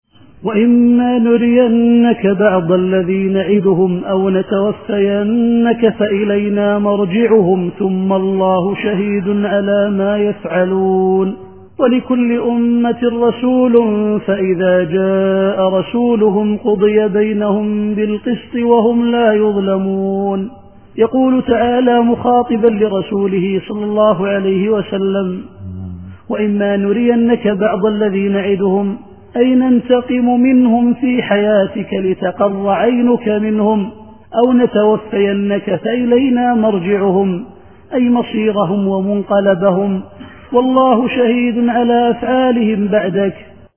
التفسير الصوتي [يونس / 46]